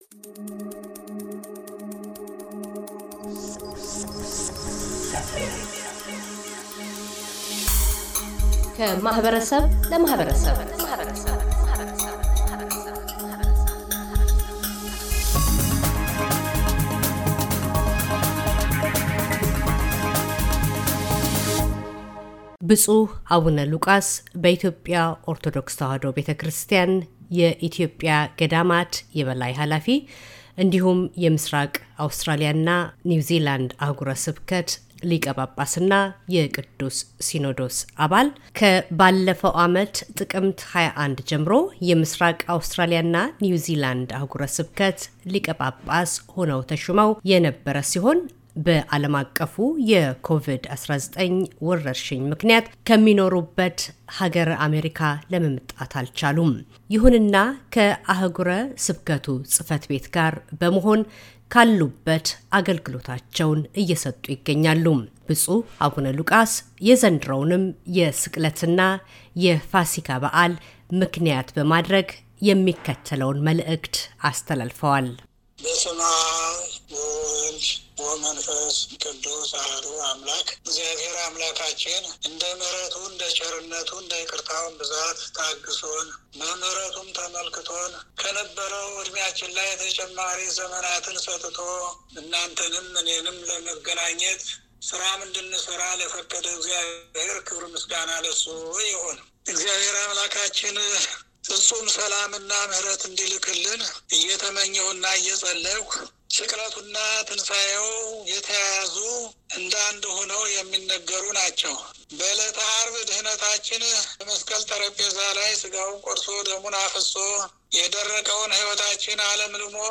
ብፁዕ አቡነ ሉቃስ - በኢትዮጵያ ኦርቶዶክስ ተዋሕዶ ቤተክርስቲያን የኢትዮጵያ ገዳማት የበላይ ኃላፊ፣ የምሥራቅ አውስትራሊያና ኒውዚላንድ አሕጉረ ስብከት ሊቀጳጳስና የቅዱስ ሲኖዶስ አባል፤ የስቅለትና ትንሣኤ በዓል መልዕክታቸውን ያስተላልፋሉ።